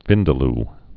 (vĭndə-l)